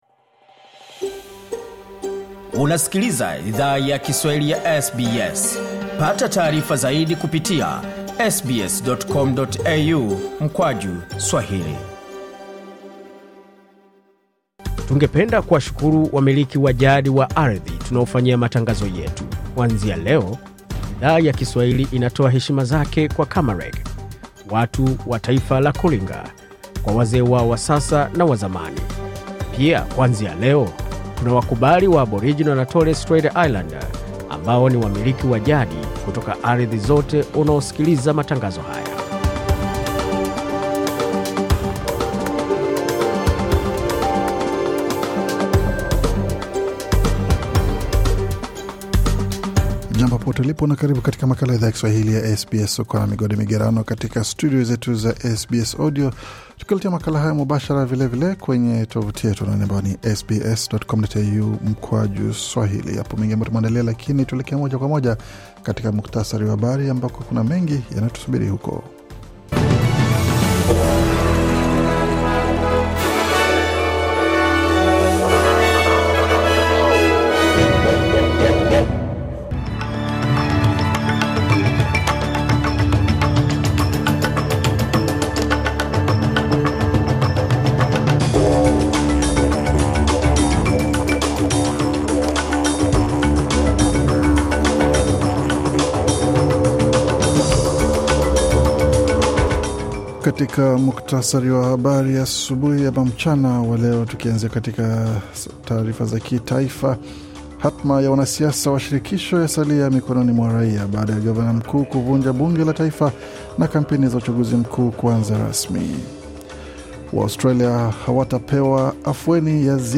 Taarifa ya Habari 28 Machi 2025